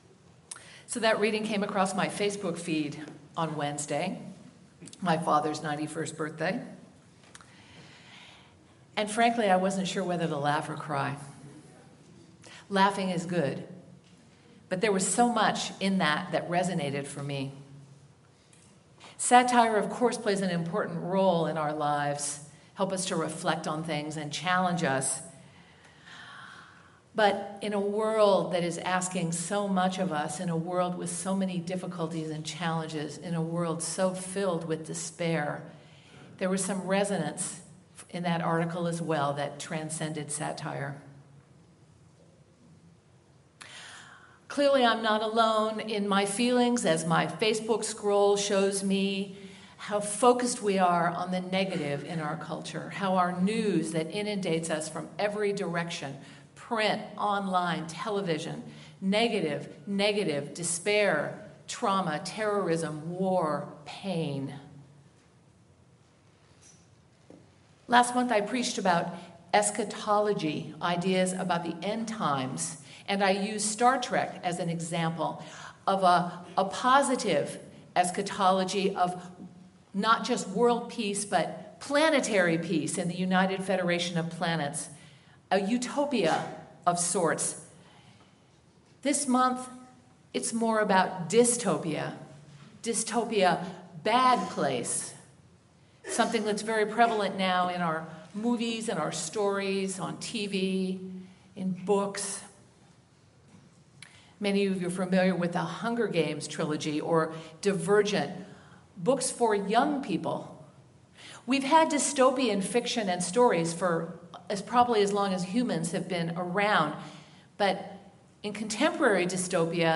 Sermon-When-Hope-Is-Hard-to-Find.mp3